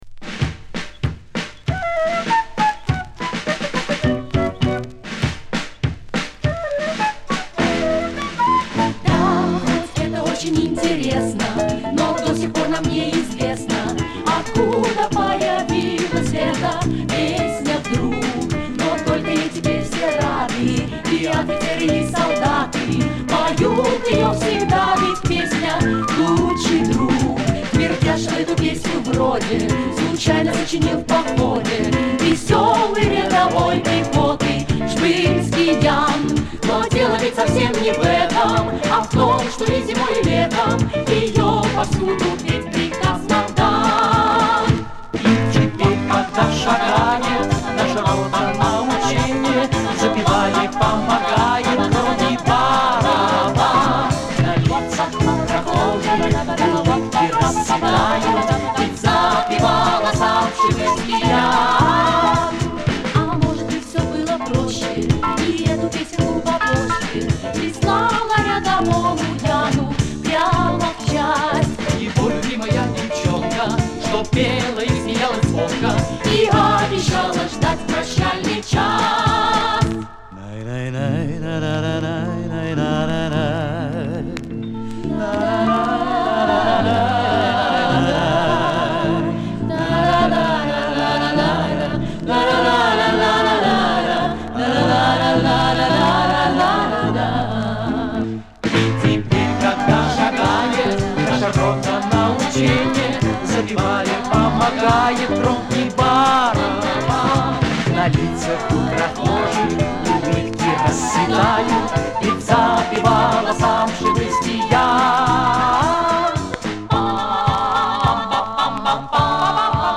Жанр: Советская эстрада
Хруст иголки порадовал
Специально оставил похрустывание,ностальгия...